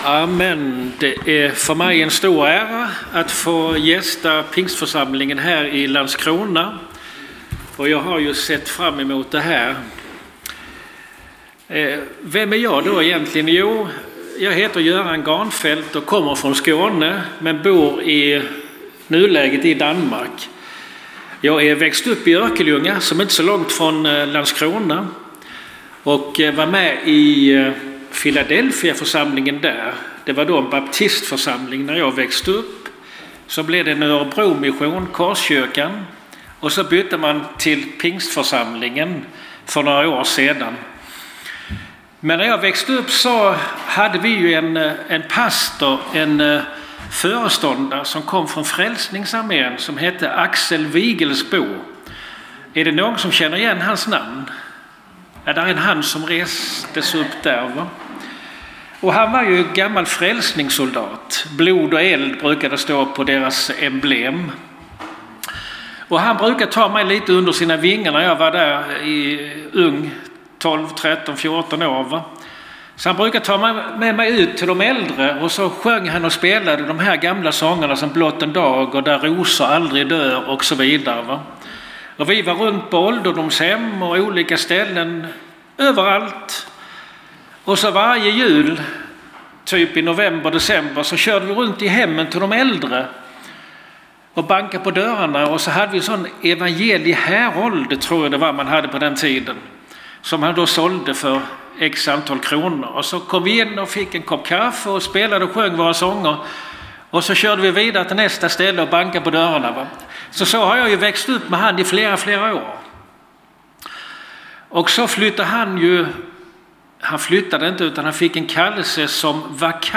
Det finns ett par ikoner uppe till höger där du kan lyssna på ”bara” predikan genom att klicka på hörlurarna eller ladda ner den genom att klicka på pilen.